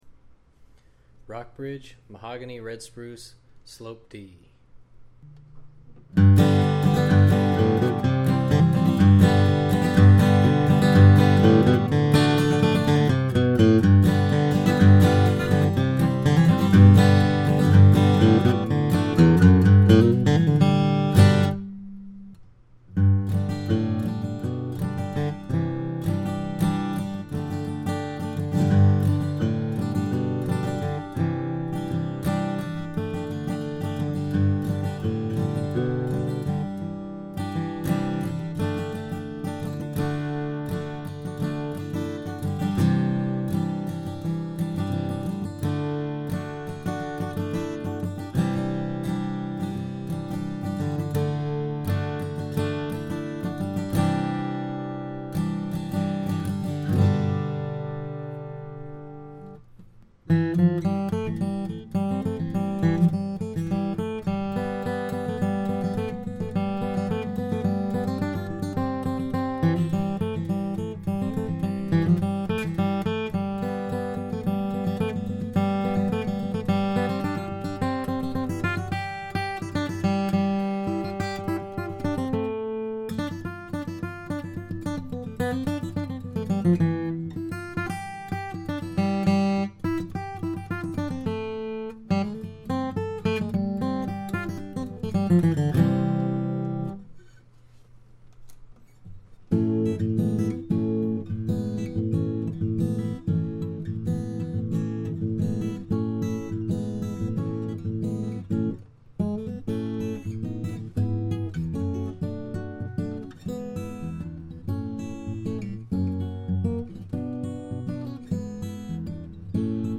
Soundboard: Red Spruce
Back/Sides: Honduras Mahogany
Body Style: Slope Dread